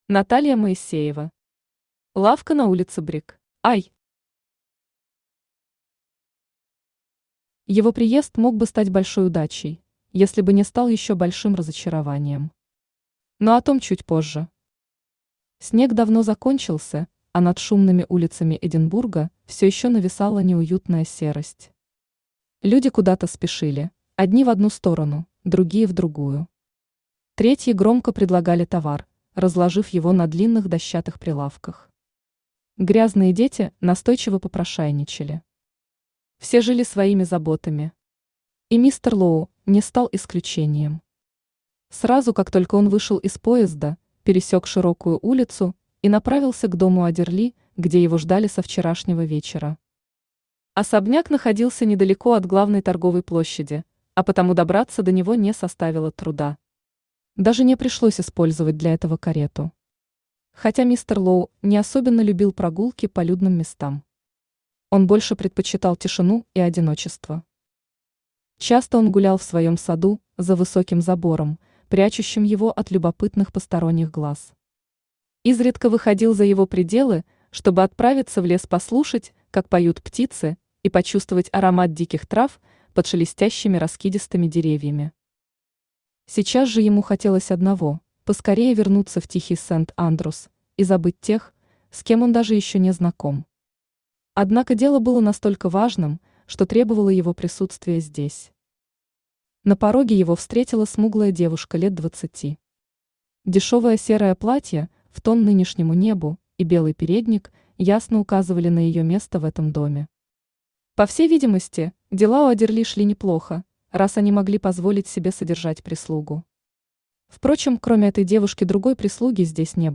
Aудиокнига Лавка на улице Брик Автор Наталья Моисеева Читает аудиокнигу Авточтец ЛитРес.